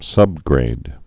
(sŭbgrād)